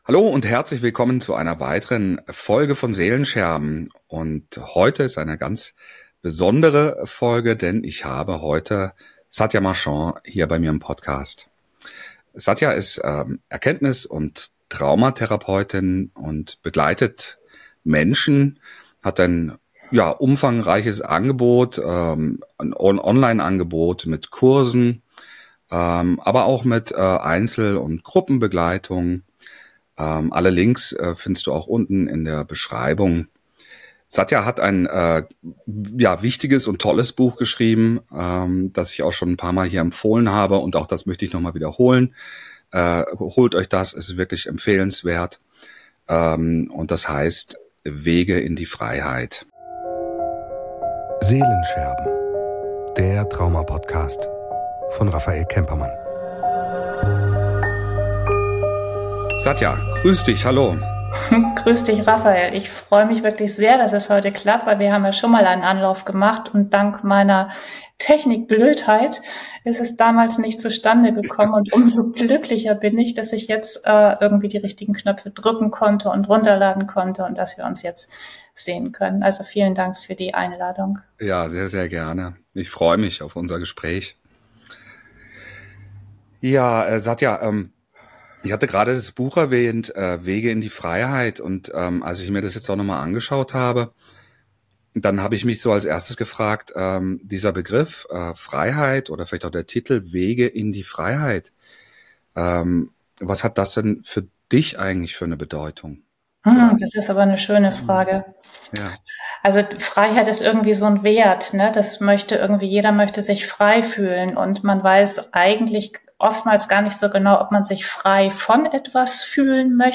Heilung für die Seele – Spirituelle Transformation: Ein Gespräch